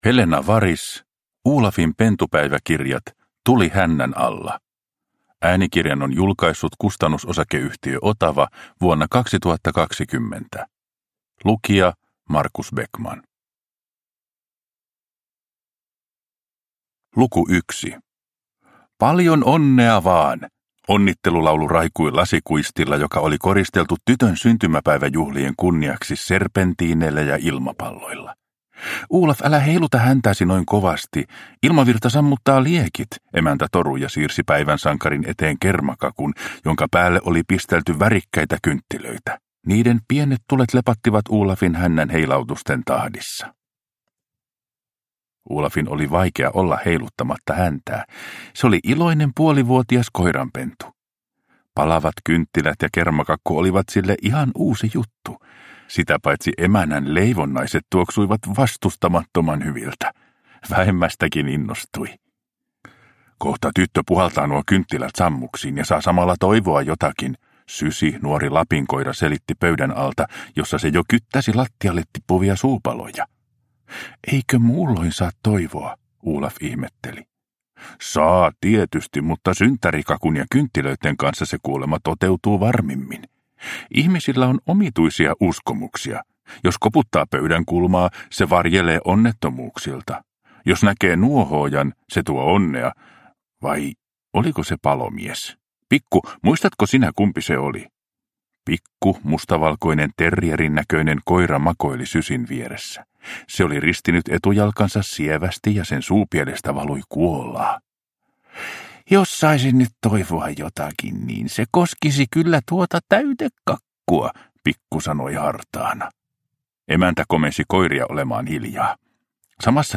Olafin pentupäiväkirjat - Tuli hännän alla – Ljudbok – Laddas ner